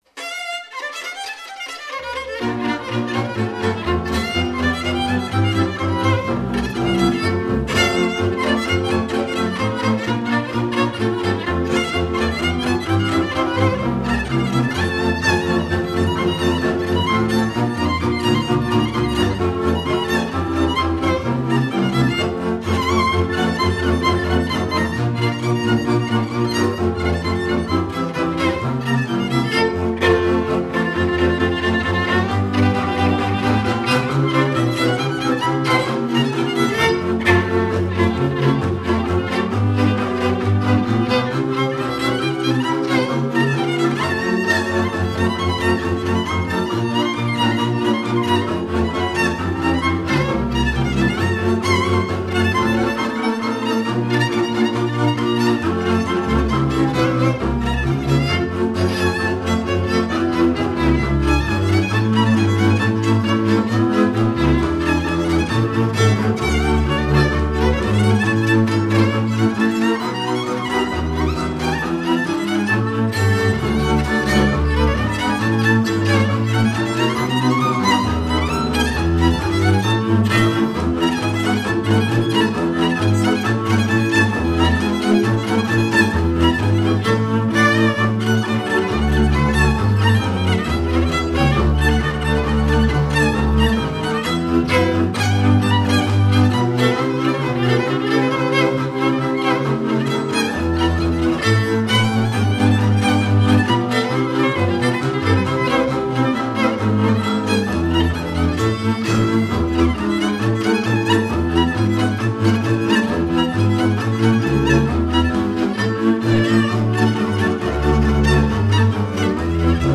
Fecioreşte des şi rar – Sűrű és ritka tempó (04:42)